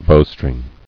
[bow·string]